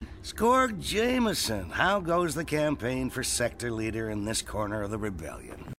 Morgan Katarn Source Dark Forces: Soldier for the Empire audio drama Original designer